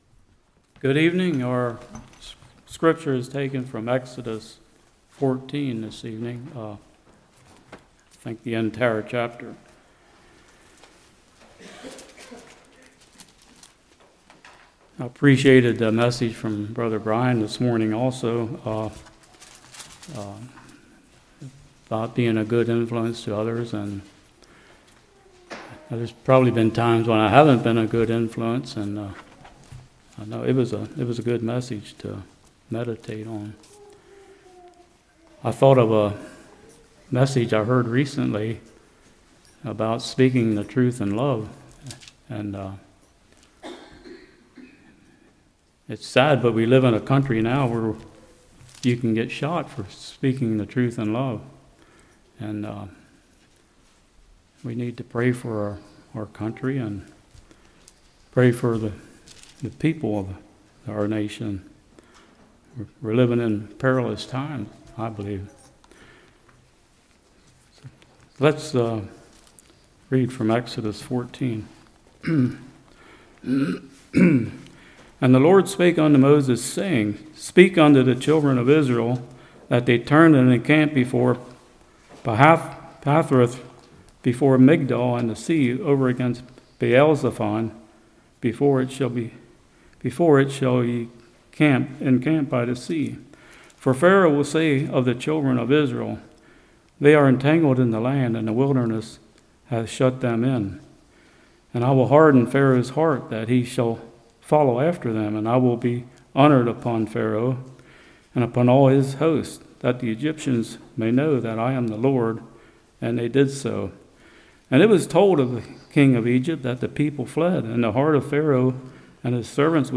Exodus 14:1-31 Service Type: Evening Pharaoh was willing to go to great lengths to get the Israelites back.